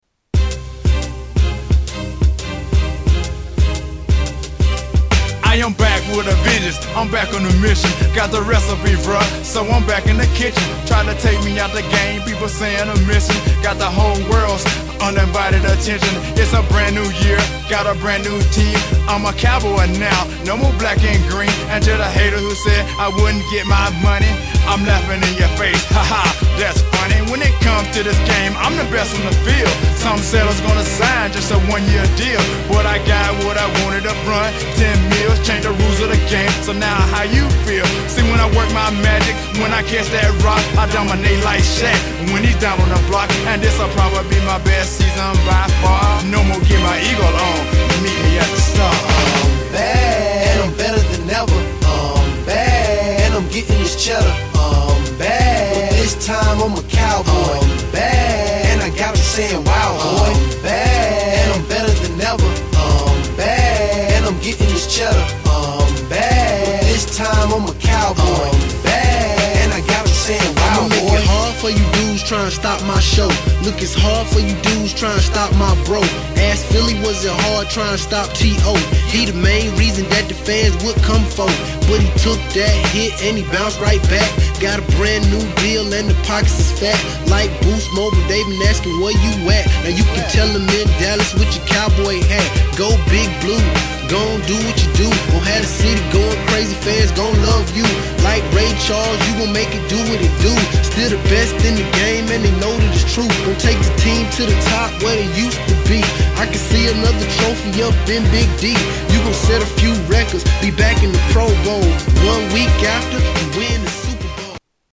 Category: Radio   Right: Personal
Tags: Sports Radio Funny George Fox College